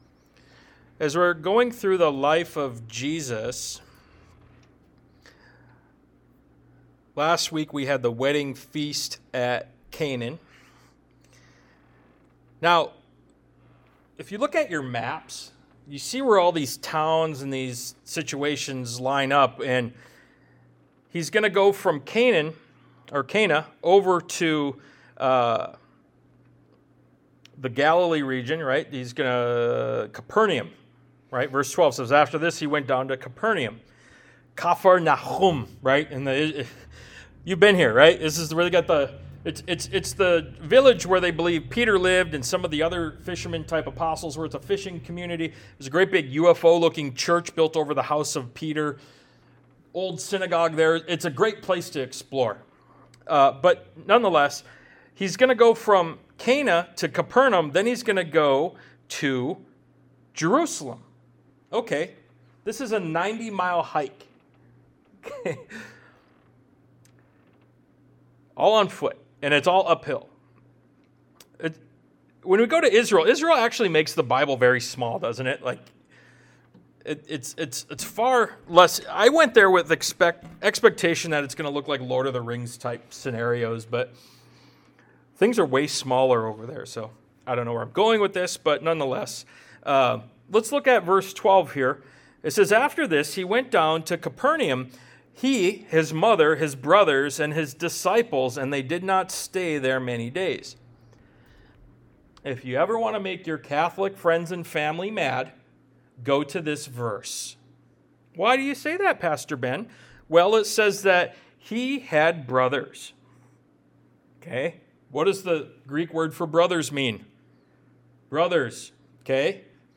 Ministry of Jesus Service Type: Sunday Morning « “Follow Me” Ministry of Jesus Part 4 “The Four Musts” The Ministry of Jesus Part 6 »